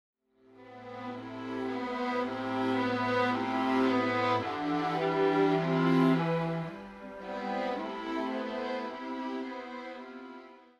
موتیف ها و ملودی های تماتیک ساده ی تکرار شونده و مینیمال